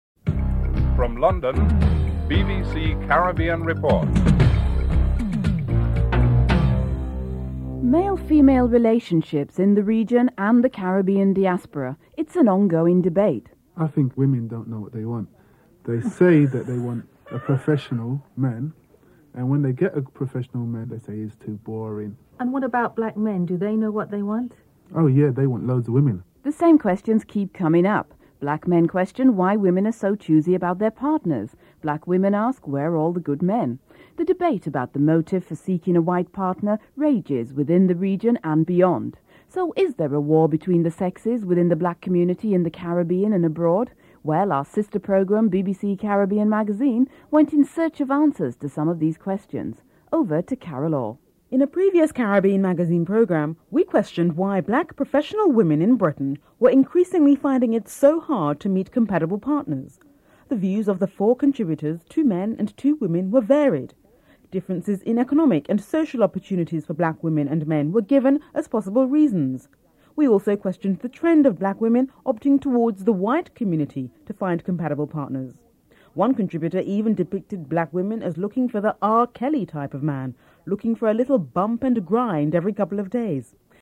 A discussion with an international footing is held to answer the question on whether there is a war between the sexes within the black community.
2. Discussion on male/female relationships to see whether a look at history can find a global answer (00:51-15:15)